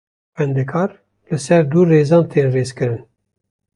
/reːˈzɑːn/